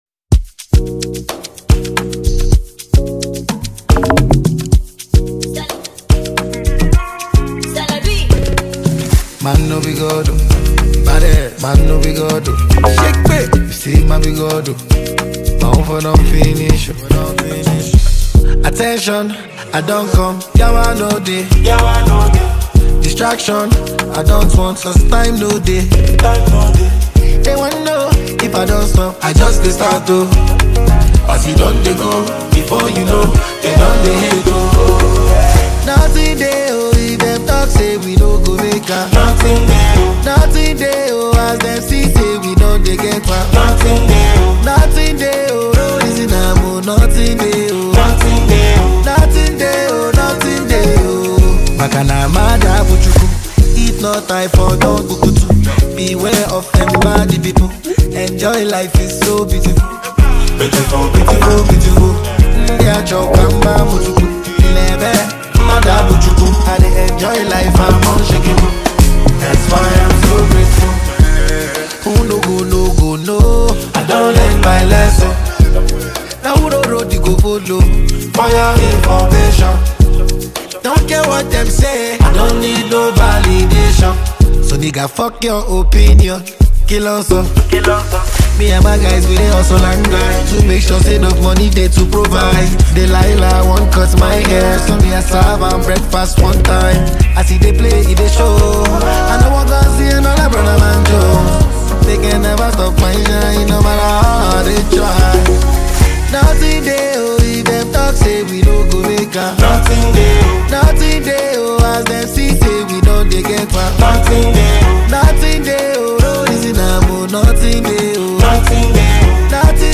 • Genre: R&B